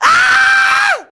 SCREAM 1.wav